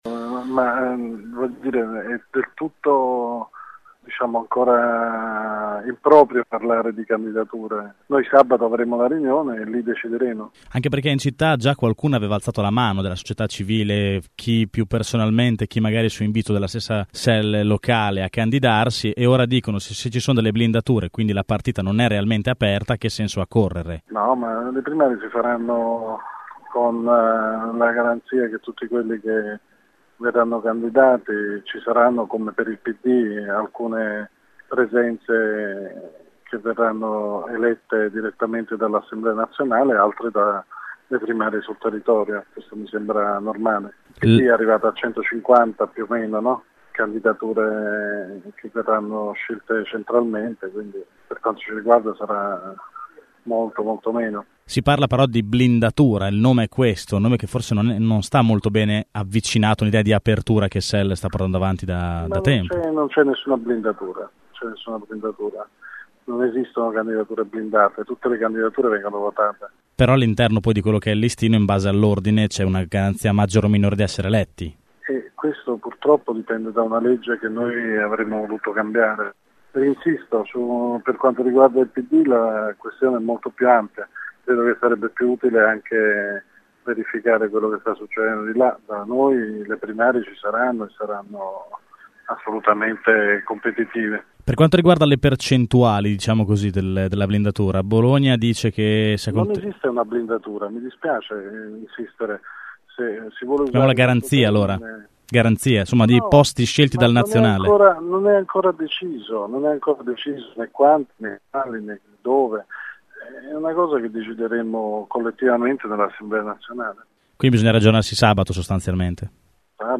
Migliore, responsabile esteri del partito di Vendola, raggiunto telefonicamente nega che ci siano “blindature“, però ammette che nella riunione di sabato il partito deciderà chi candidare e dove farlo.